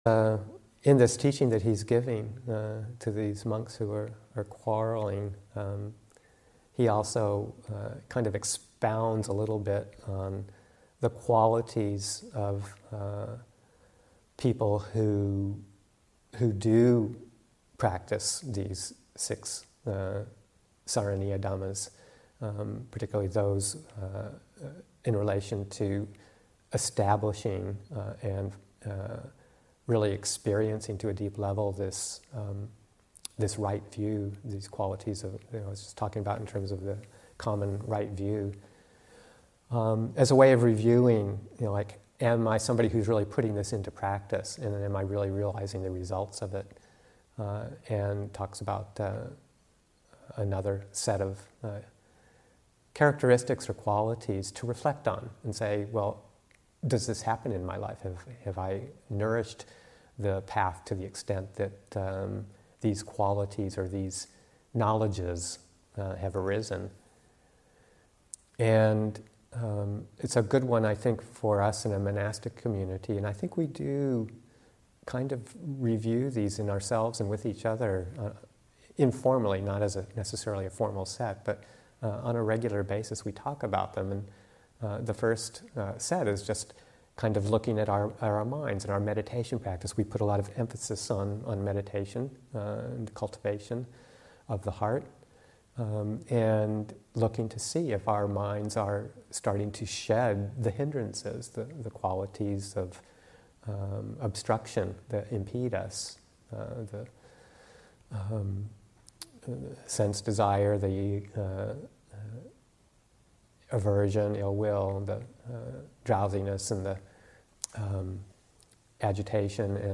11. The first two reviewing knowledges of one who has made progress on the path: Freedom from the hindrances and serenity of mind. Teaching